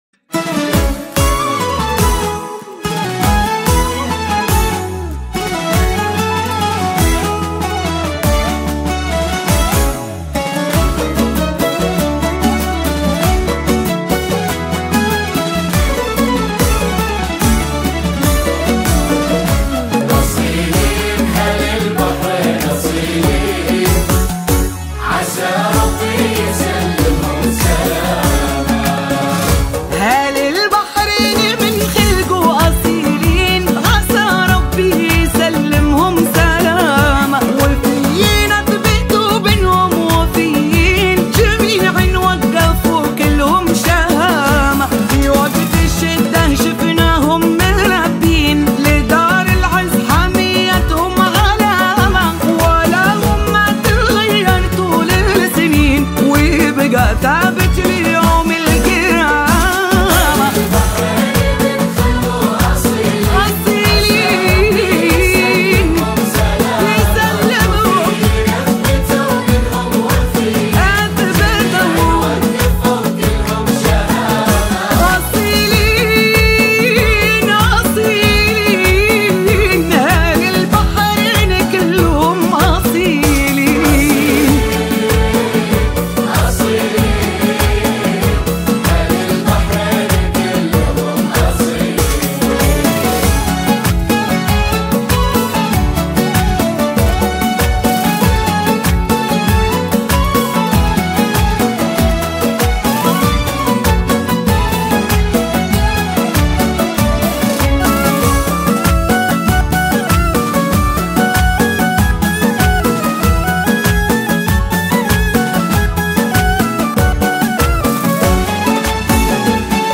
• توزيع موسيقي فخم
• كورال قوي
• قوة الصوت
• الإحساس العالي